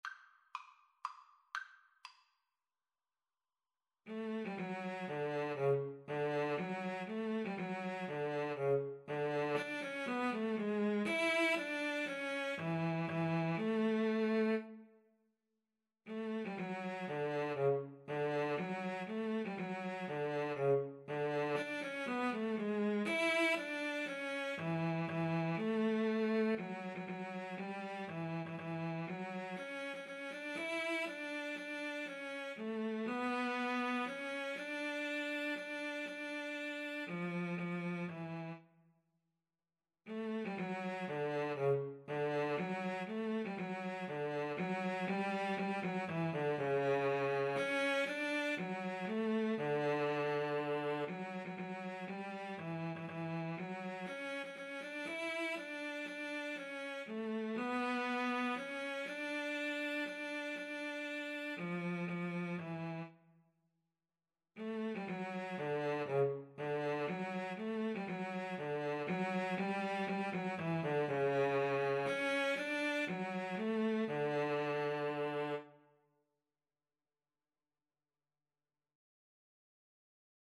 3/4 (View more 3/4 Music)
Allegro (View more music marked Allegro)
Classical (View more Classical Viola-Cello Duet Music)